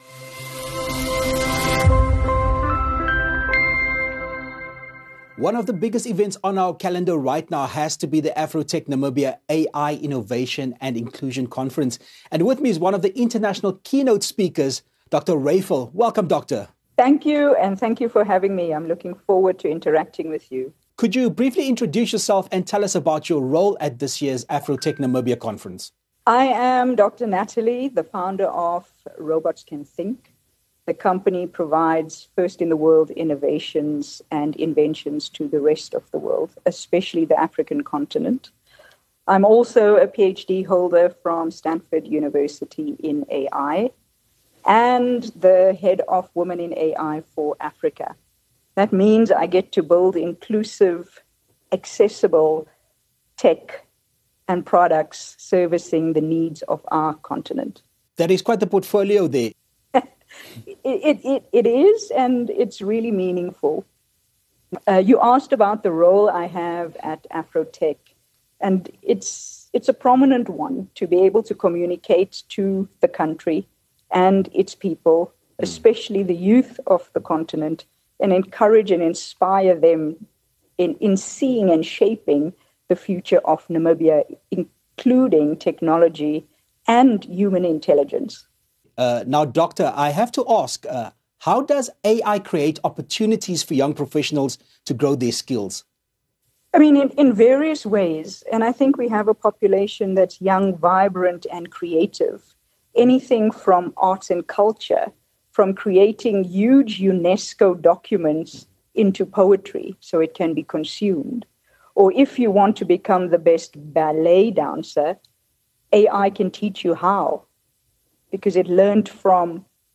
In her AfroTech Namibia interview